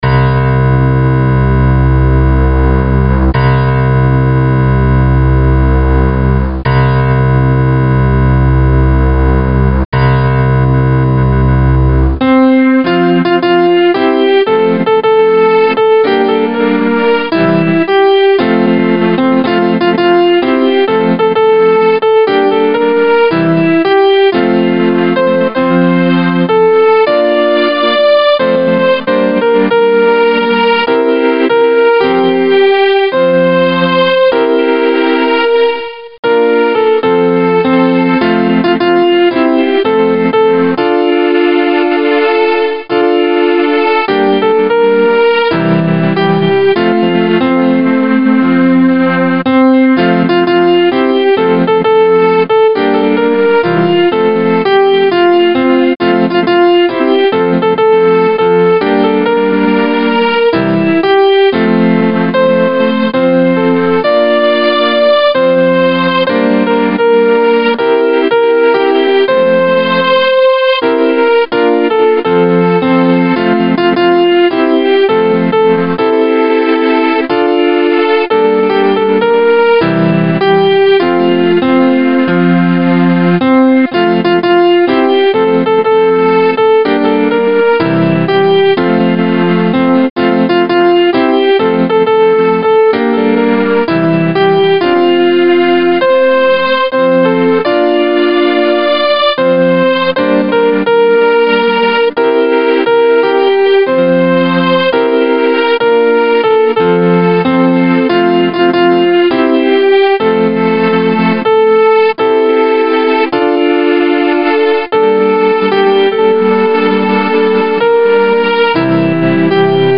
keyboard
Voice used: Grand piano
Tempo: moderately Origin: German